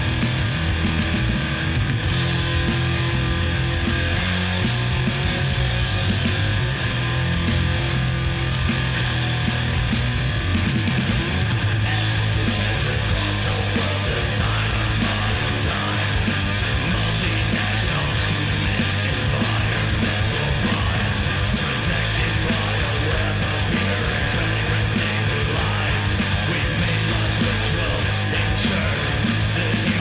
até irromperem os riffs downtuned, thrashy
alternados entre solos curtos e d-beats implacáveis
principalmente dentro da lógica do crust punk